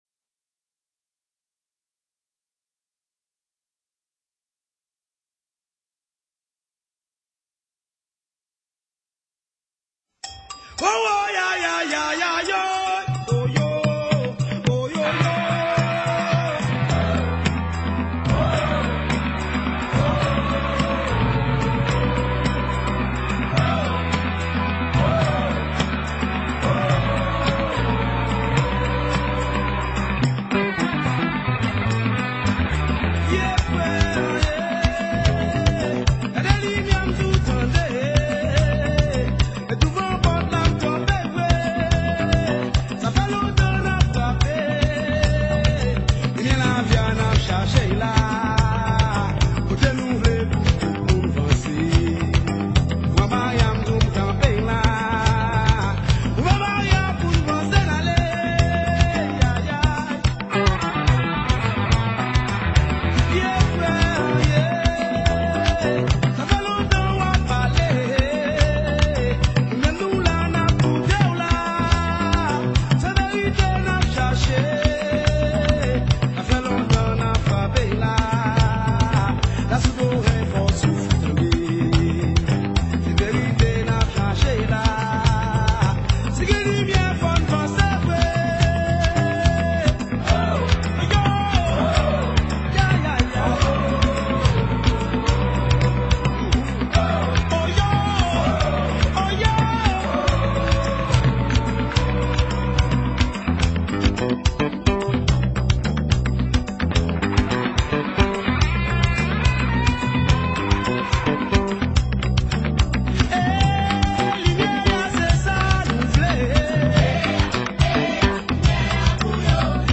Genre: Racine